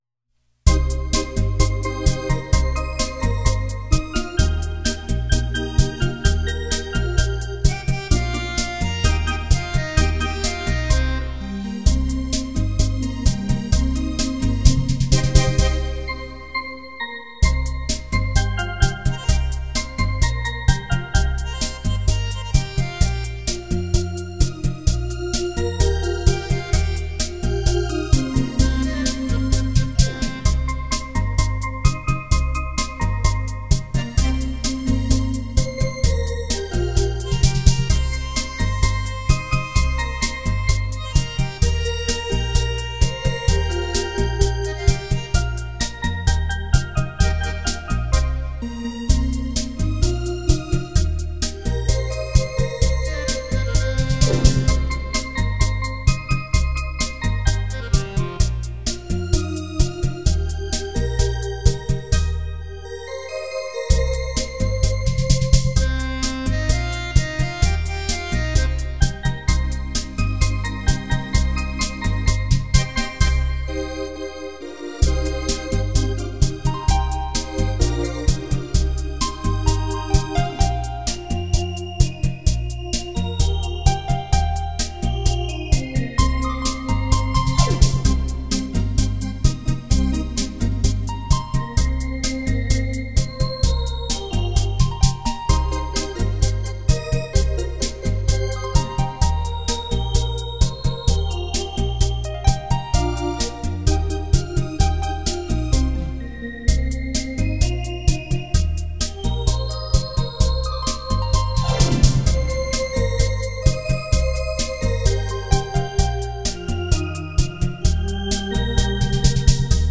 醉人的浪漫旋律+女和声